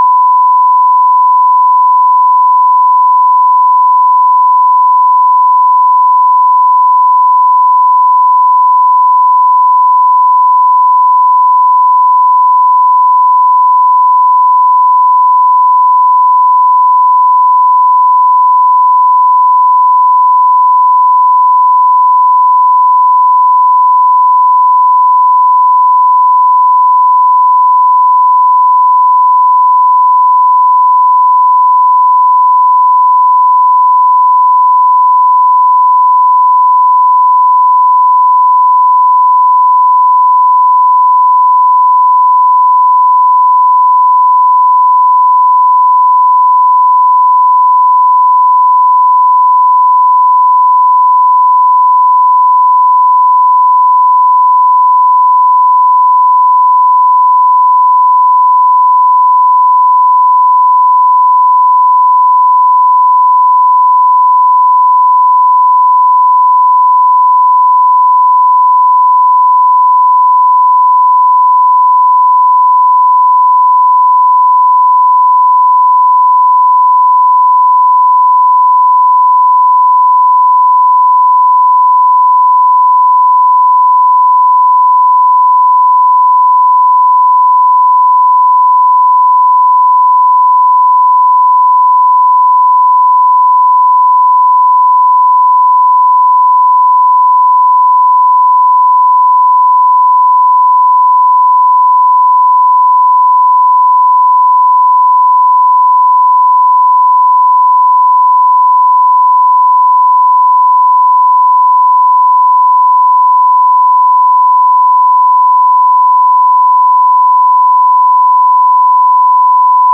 01_Tone1kHz_Baseline_(L=R-6dB Stereo)_v7 (.wav) :: MD5 Checksum : c1b9775aadfc084883c0b5102d721611